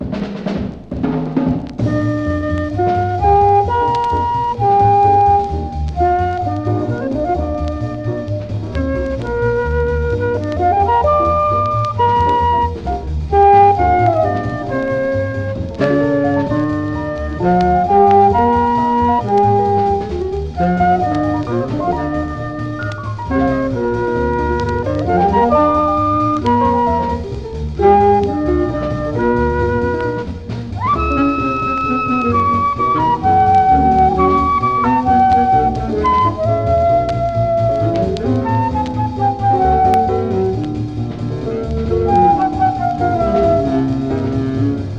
粒立ちの良い音が軽やかかつ流麗に、ときにスウィンギンに。
Jazz　USA　12inchレコード　33rpm　Mono